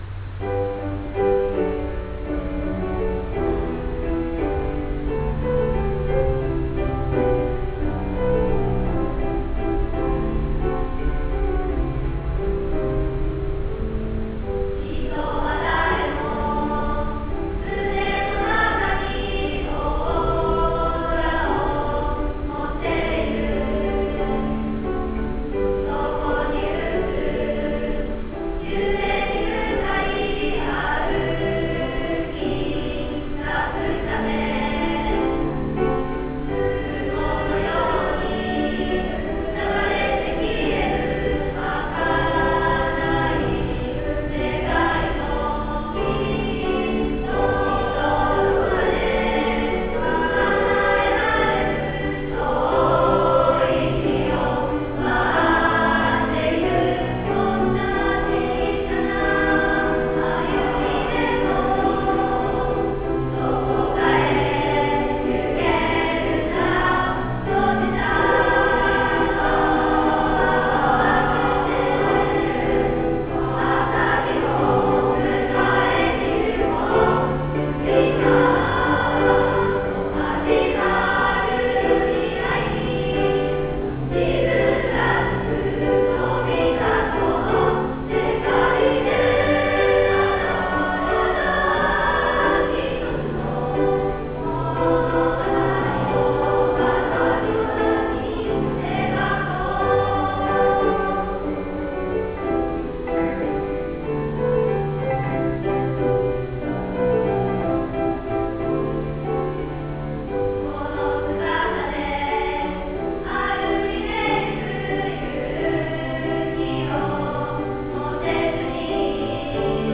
下の曲名をクリックすると、当日のハーモニーを聞くことができます。